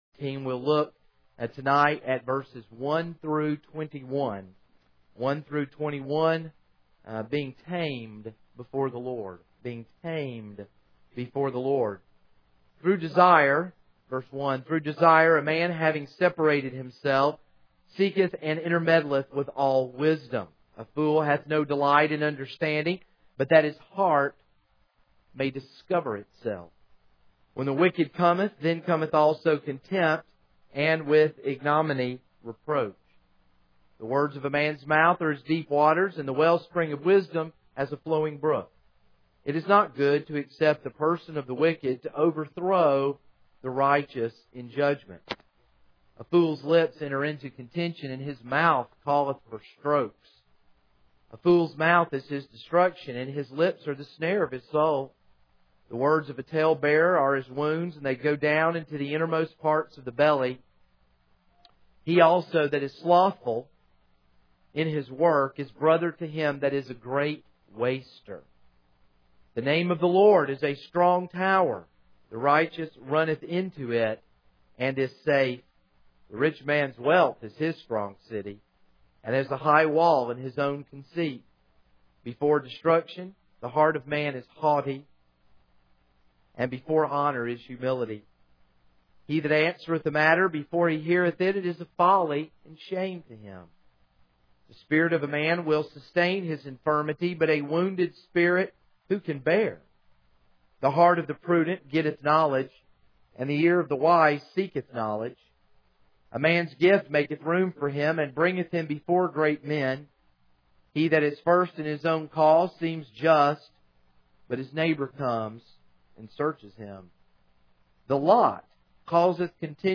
This is a sermon on Proverbs 18:1-21.